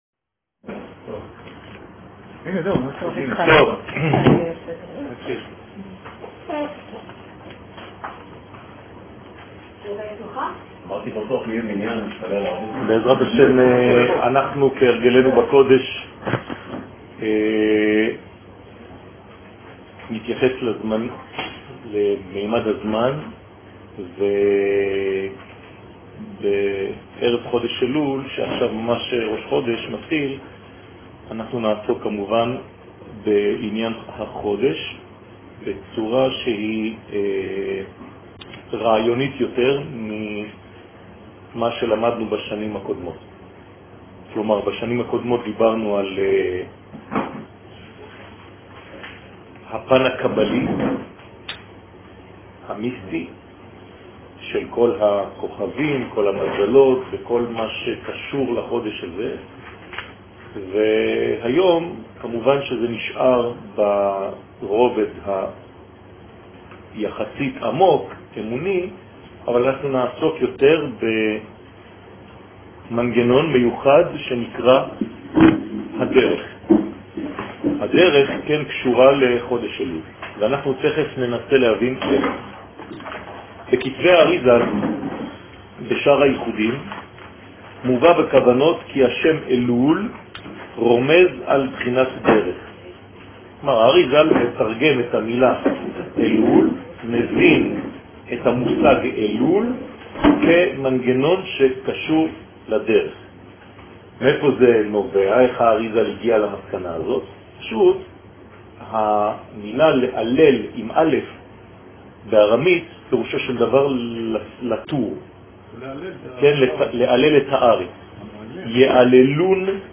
שיעור ראש חודש אלול תשע"ז